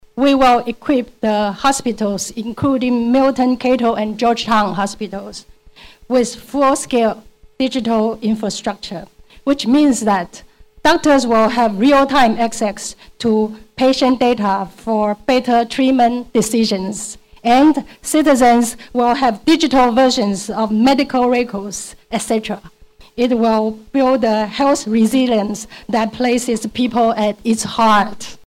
Ambassador Fan was speaking at the ceremony to mark Taiwan’s 114 th anniversary on October 7.